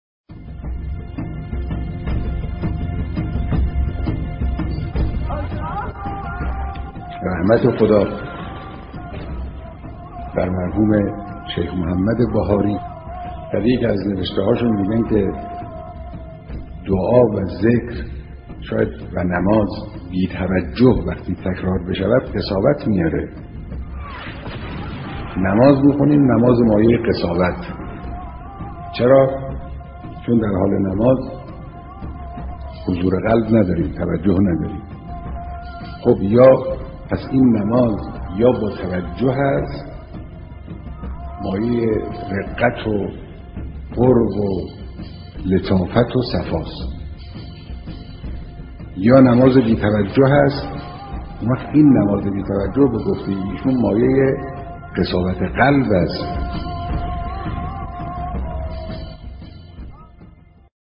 کلیپ-شماره-1-سخنرانی-رهبر-درباره-آثار-نماز-بی-توجه-از-قول-شیخ-محمد-بهاریره-می-باشد.mp3